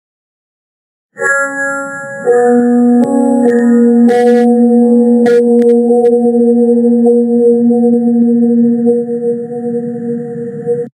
Звук пугающего баса в Roblox